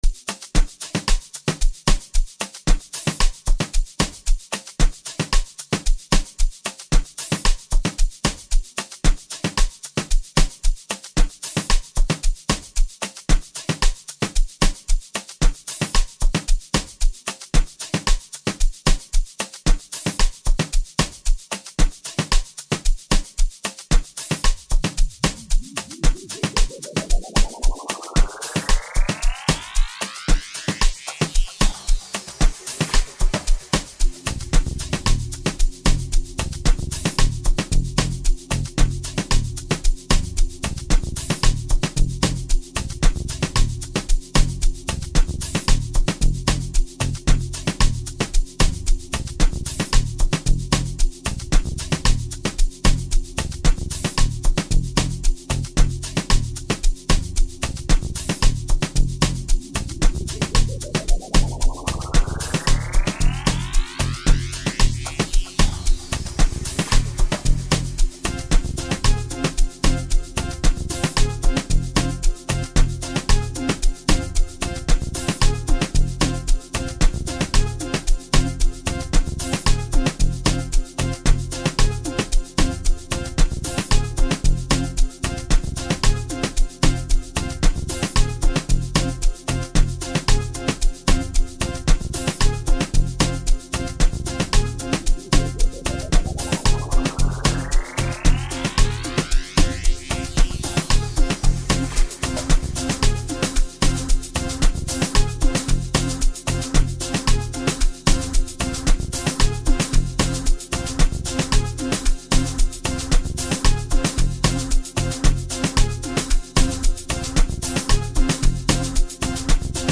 Home » Amapiano